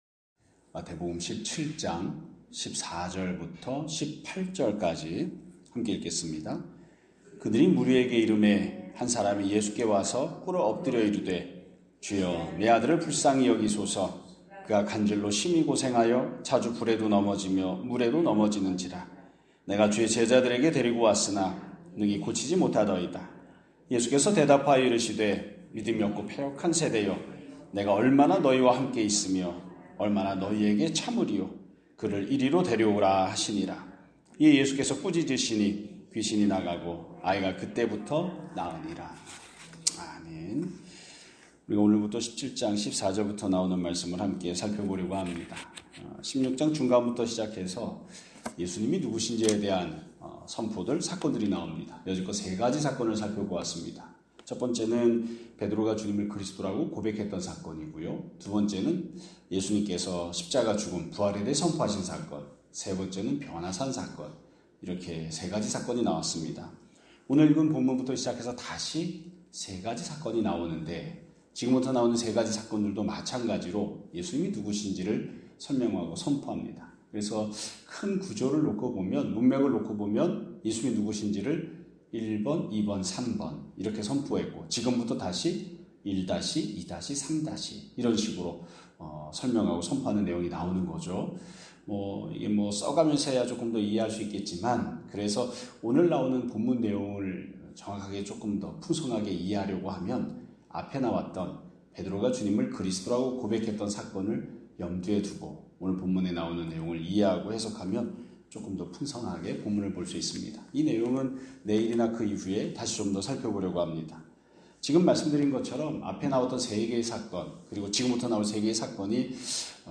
2025년 11월 26일 (수요일) <아침예배> 설교입니다.